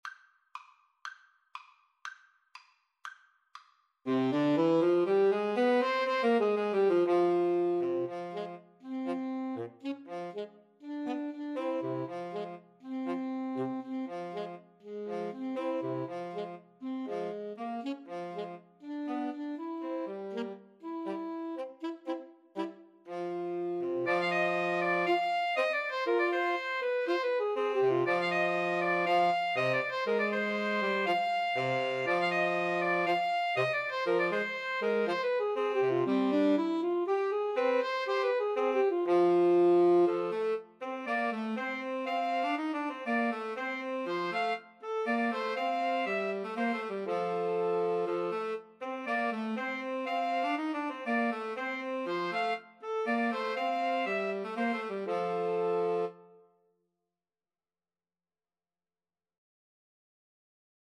Soprano SaxophoneAlto SaxophoneTenor Saxophone
C minor (Sounding Pitch) (View more C minor Music for Woodwind Trio )
Allegro (View more music marked Allegro)
2/4 (View more 2/4 Music)